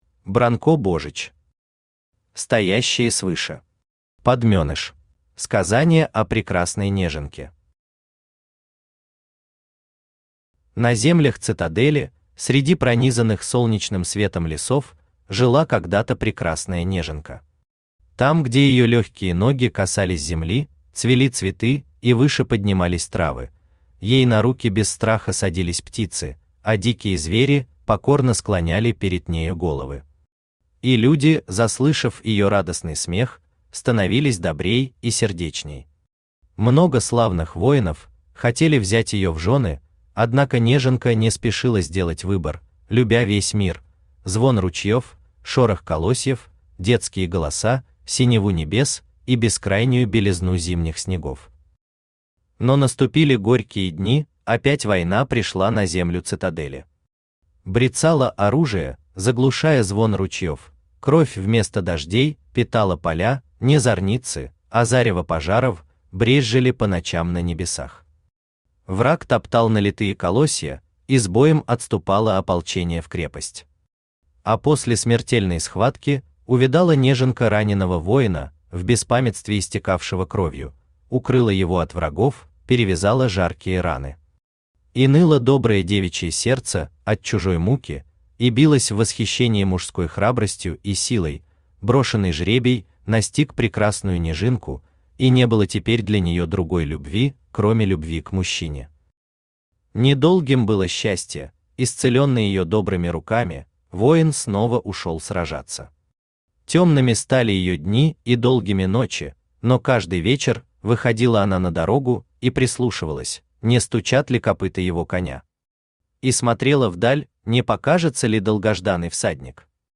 Аудиокнига Стоящие свыше. Подменыш | Библиотека аудиокниг
Подменыш Автор Бранко Божич Читает аудиокнигу Авточтец ЛитРес.